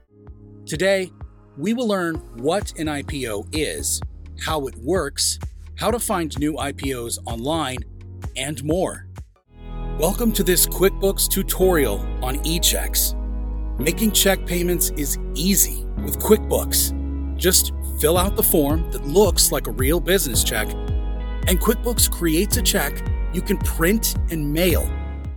Demo Reels
With a deep, rich, and warm voice, I bring a unique and captivating tone to every project.
explainerdemo.mp3